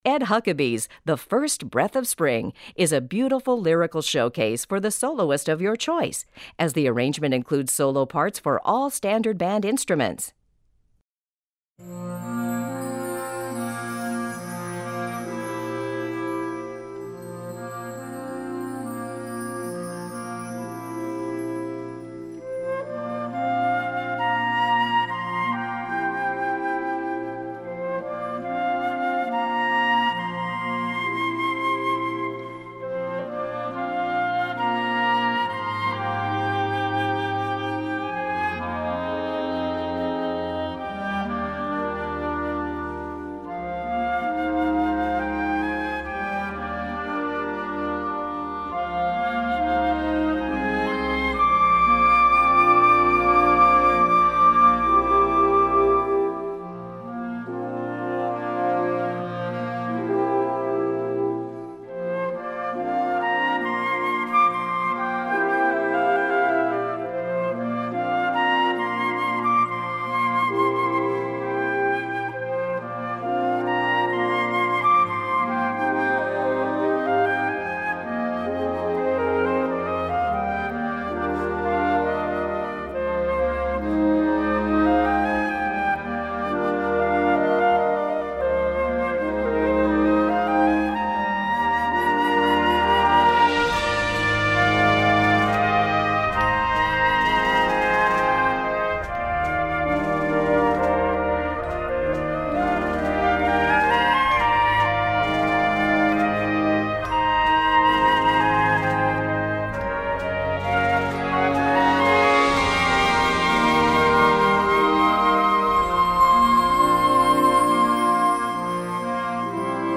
Besetzung: Blasorchester
in einem Solowerk mit Blasorchesterbegleitung